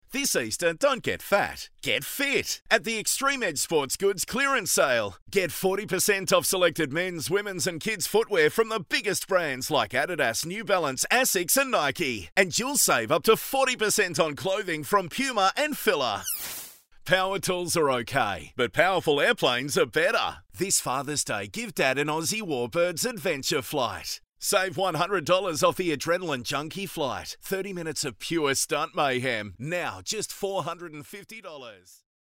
• Hard Sell
• Neumann TLM103 / Rode NT2a / Sennheiser MKH416